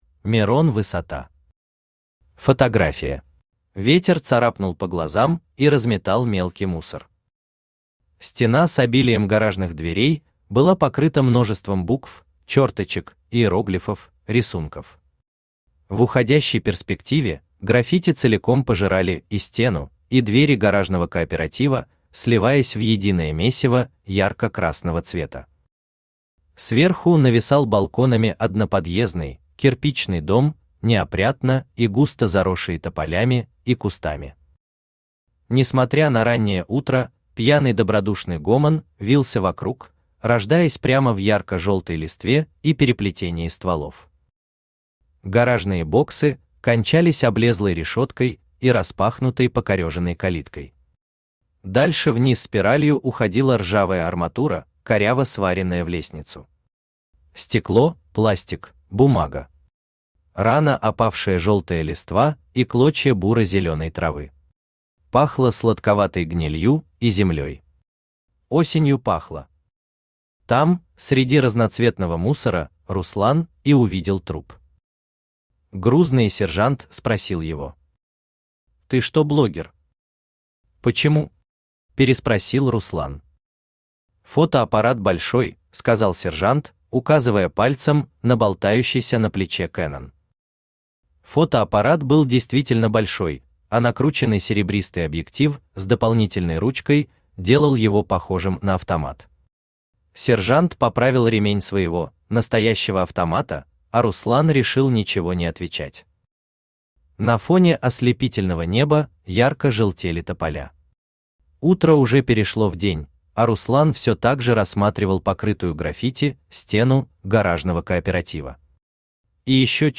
Aудиокнига Фотография Автор Мирон Высота Читает аудиокнигу Авточтец ЛитРес. Прослушать и бесплатно скачать фрагмент аудиокниги